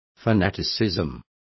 Complete with pronunciation of the translation of fanaticism.